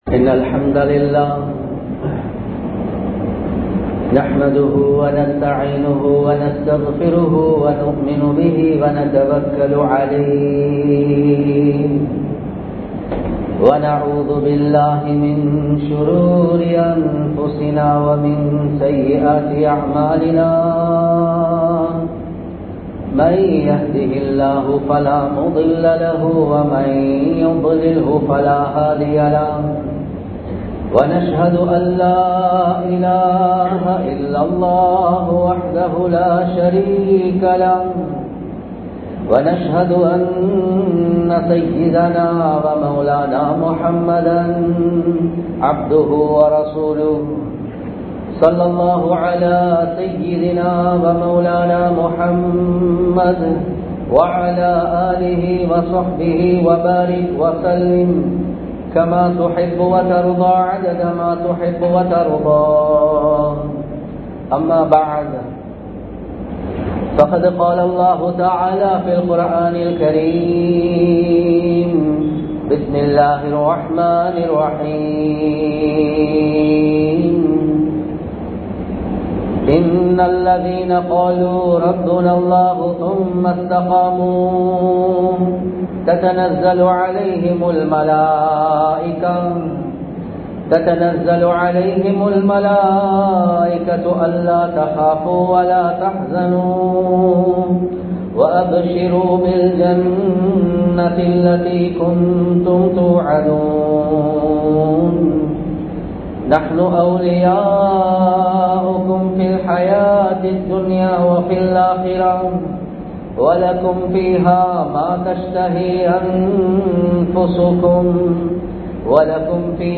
முஹர்ரம் கூறும் படிப்பினை | Audio Bayans | All Ceylon Muslim Youth Community | Addalaichenai
Colombo 15, Mattakkuliya, Kandauda Jumua Masjidh